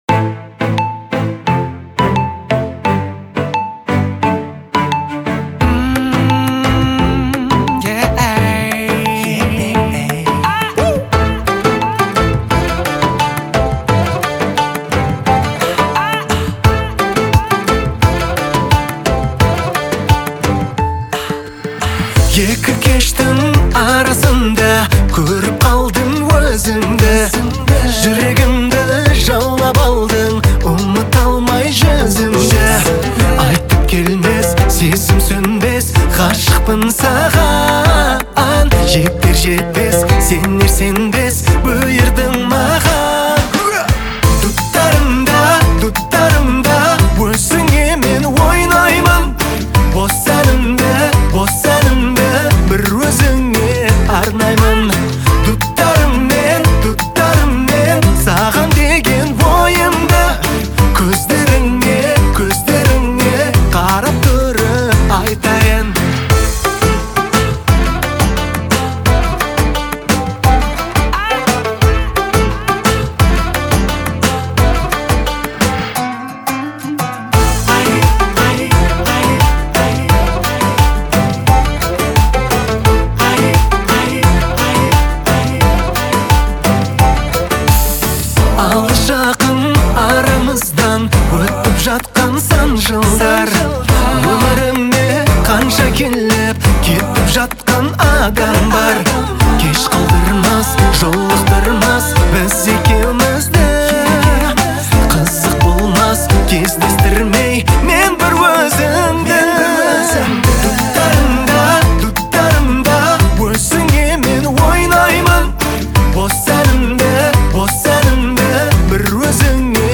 поп-фолк